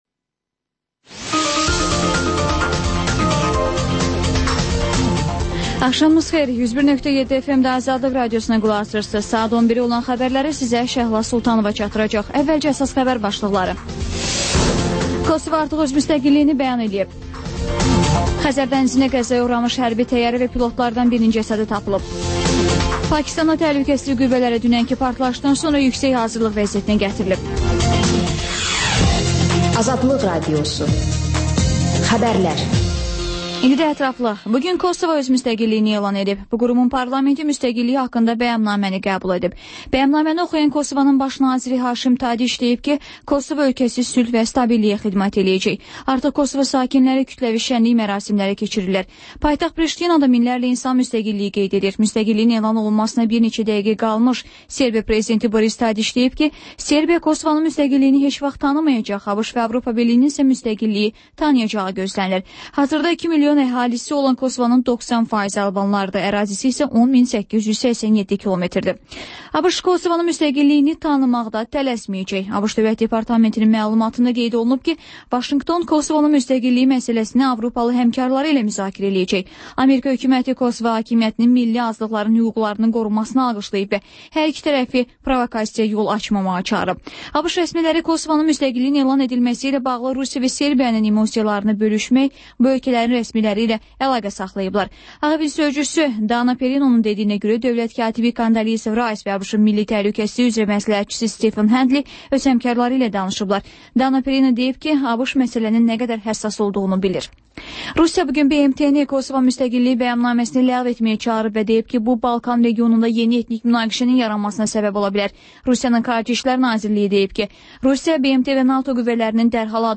Xəbərlər, İZ: Mədəniyyət proqramı və TANINMIŞLAR verilişi: Ölkənin tanınmış simalarıyla söhbət